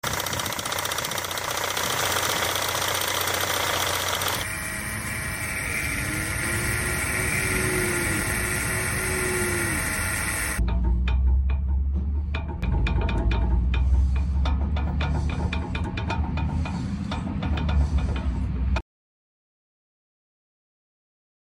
Most Popular Car Issues with sound effects free download